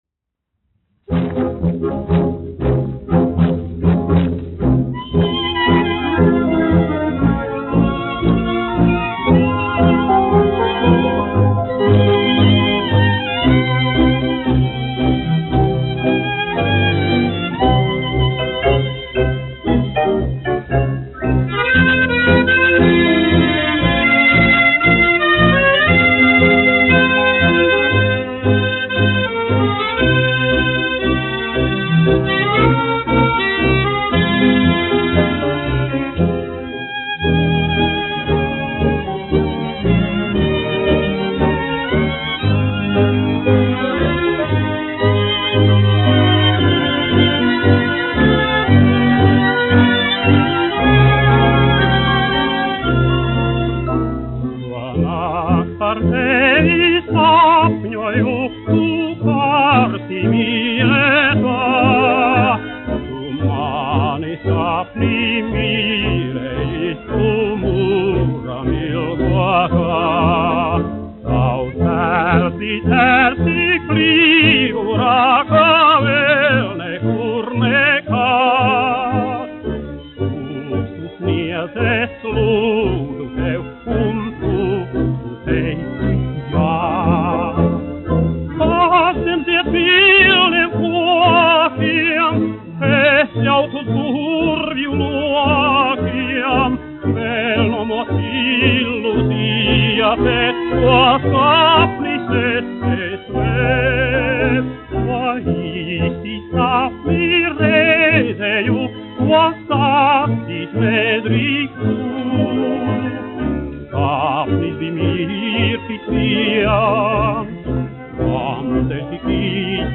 1 skpl. : analogs, 78 apgr/min, mono ; 25 cm
Operetes--Fragmenti
Skaņuplate